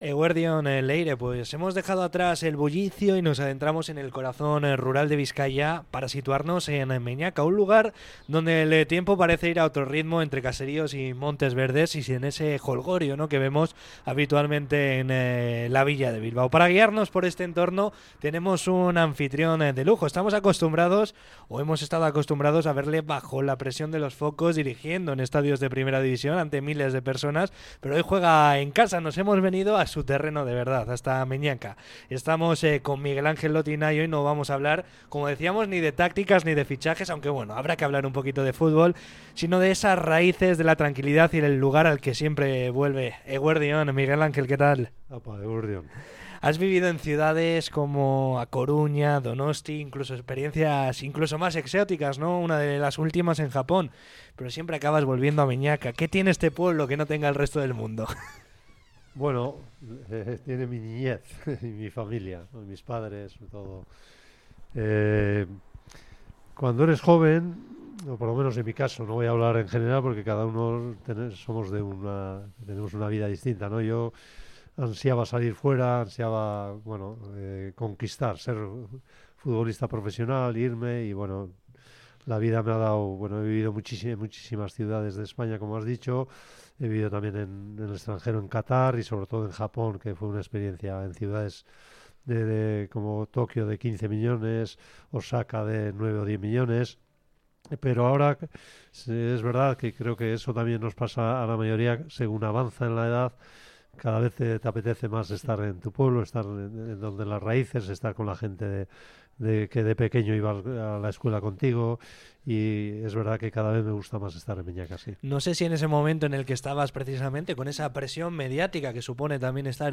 El entrenador de Meñaka nos recibe en su pueblo natal, el lugar donde deja de ser el "míster" para volver a ser "el rubio"